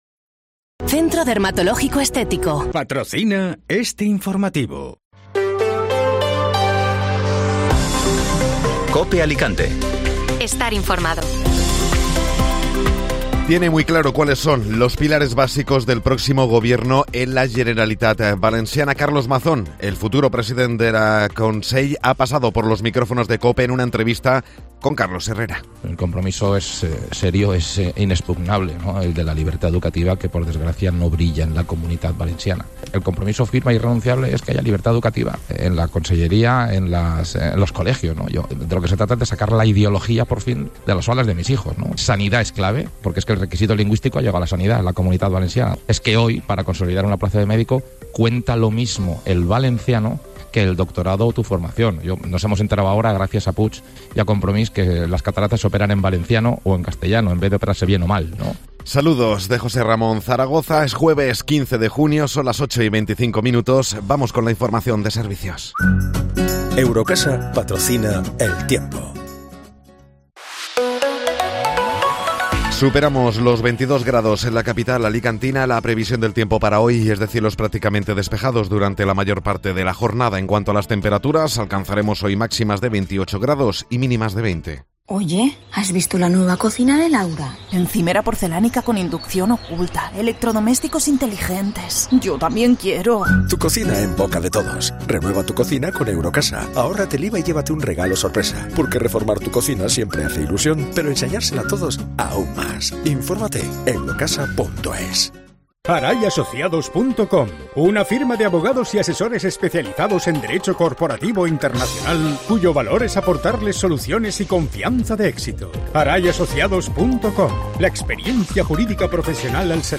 Informativo Matinal (Jueves 15 de Junio)